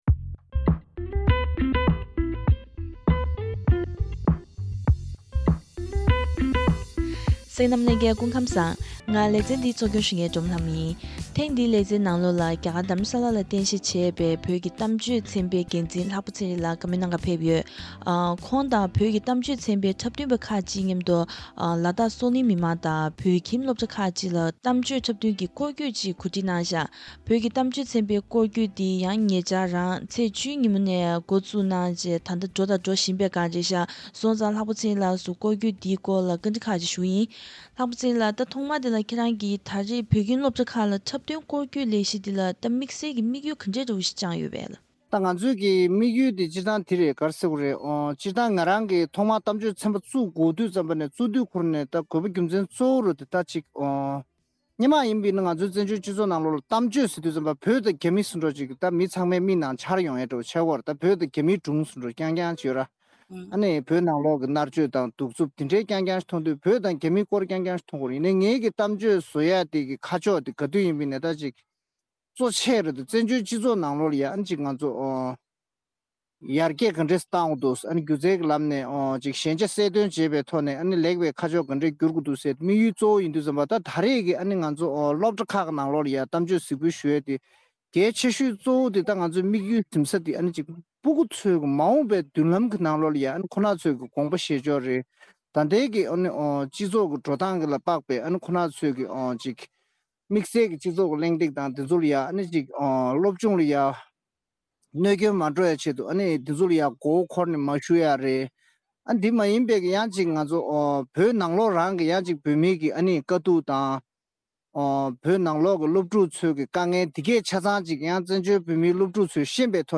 བཀའ་འདྲི་ཞུས་པ་ཞིག་གཤམ་ལ་གསན་གནང་གི་རེད།